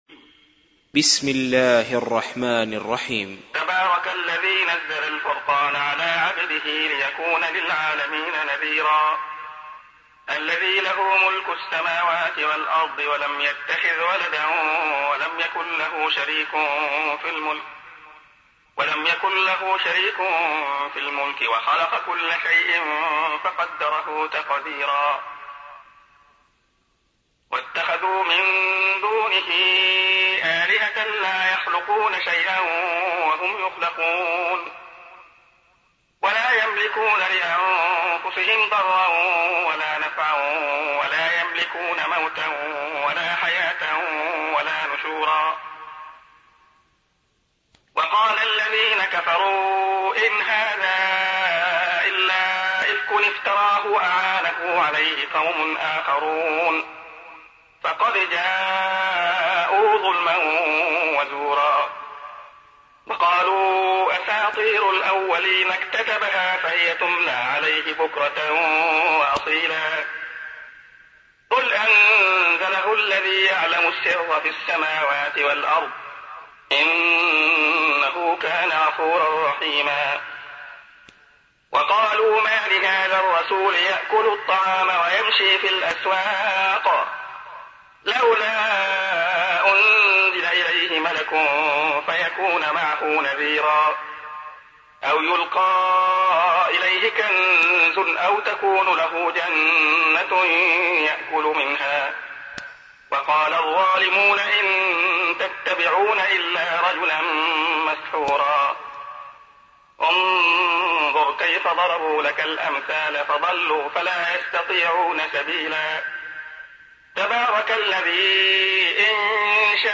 سُورَةُ الفُرۡقَانِ بصوت الشيخ عبدالله الخياط